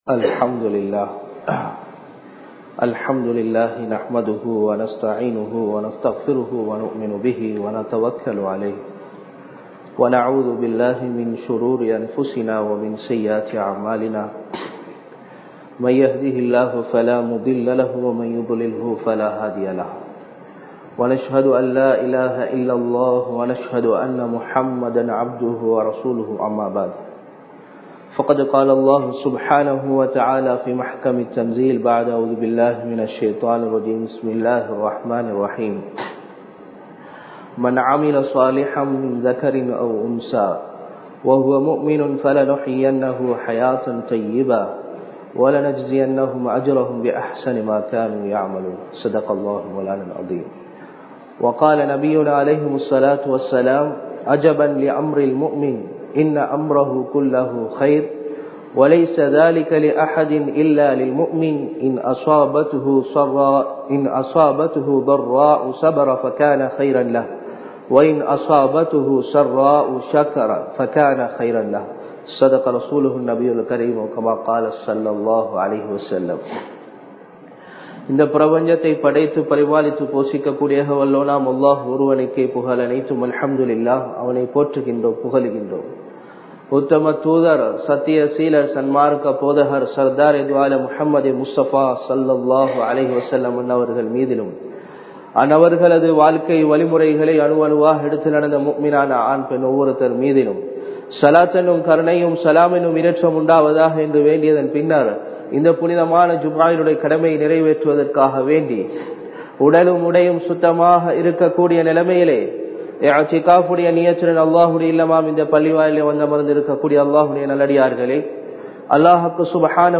Sirantha Vaalkai Veanduma? (சிறந்த வாழ்க்கை வேண்டுமா?) | Audio Bayans | All Ceylon Muslim Youth Community | Addalaichenai
Kandy, Aruppola, Sayf Jumua Masjidh 2020-02-28 Tamil Download